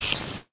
Звуки пауков
Все звуки оригинальные и взяты прямиком из игры.
Шипение/Получение урона №3
SpiderIdle3.mp3